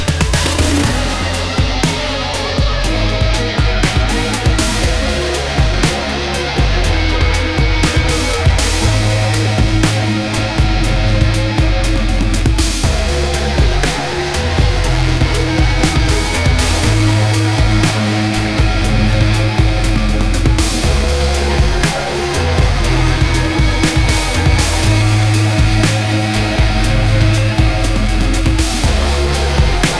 audio-to-audio moog-synth music-generation
A fine-tuning of MusicGen on a specific Moog synthesizer track